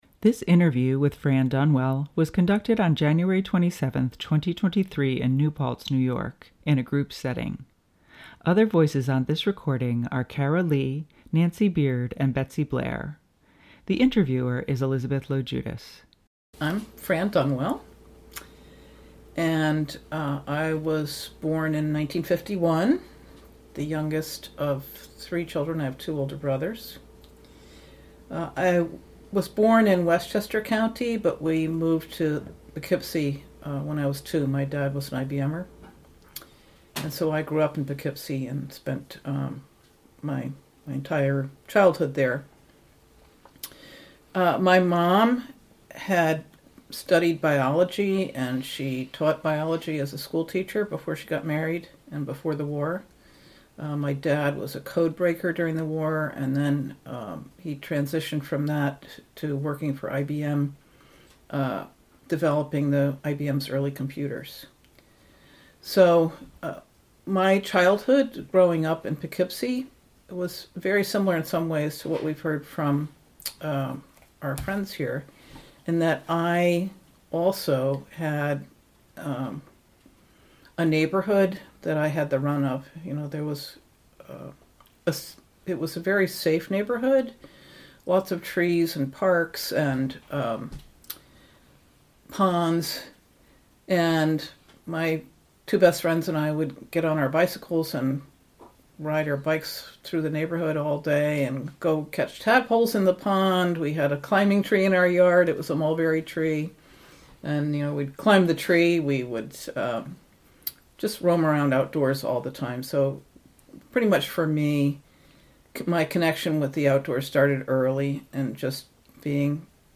Women of the River Oral Histories